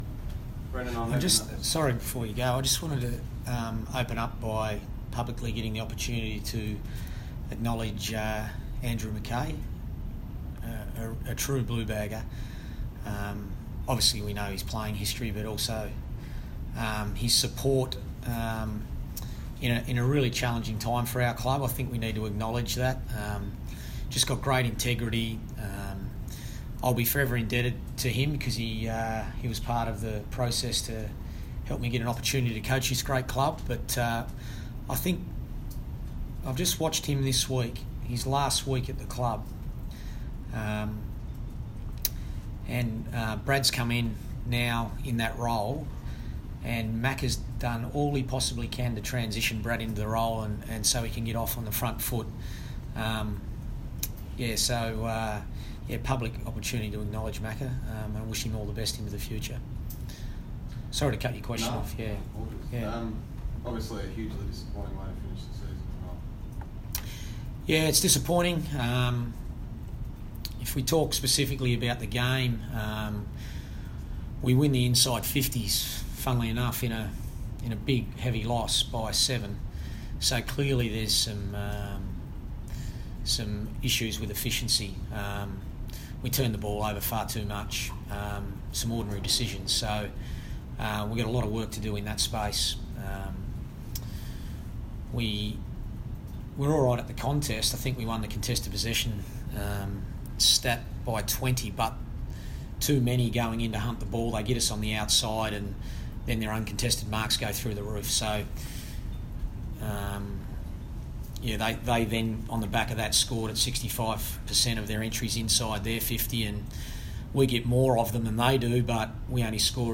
Carlton coach Brendon Bolton speaks to the media following the Blues' season-ending loss to Adelaide at Etihad Stadium.